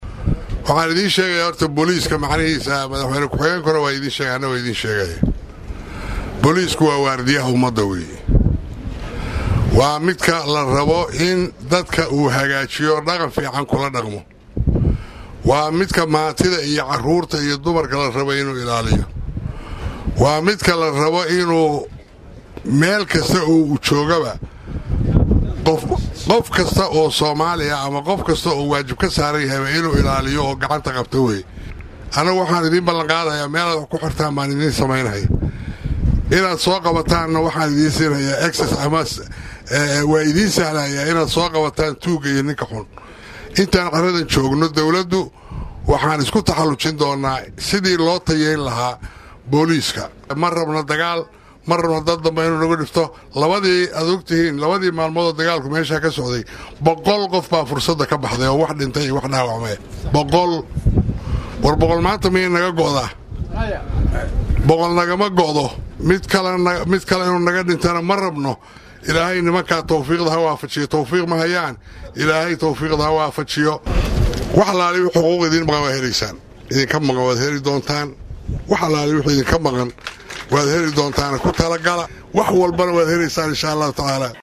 Madaxweyne Xaaf,oo ka qeybgalay Munaasabad tababar loogu soo xiray in ka badan Boqol Askari oo ka tirsan Ciidamada Booliiska Galmudug ayaa sheegay in dadaal dheer kadib uu ku guuleestay in uu soo dhiciyo lambaradii ay ku lahaayeen ciidamada Booliiska Soomaaliya.